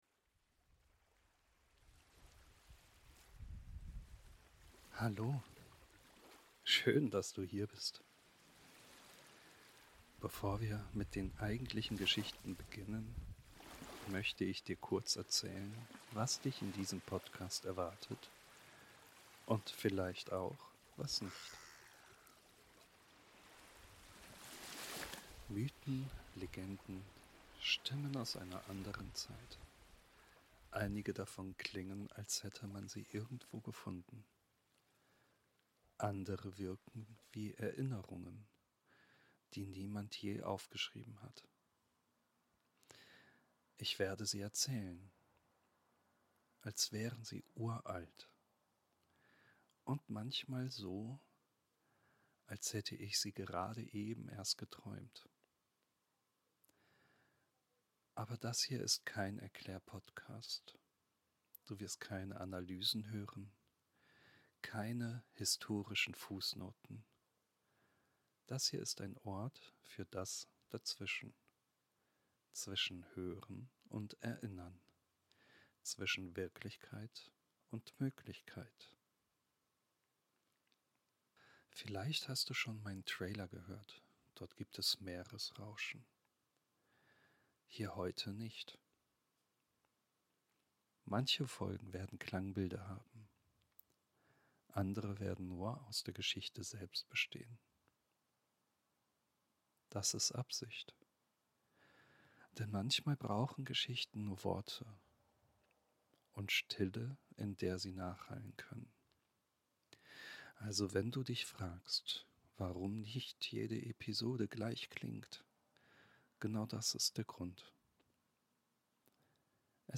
Kein lauter Auftakt, keine langen Erklärungen.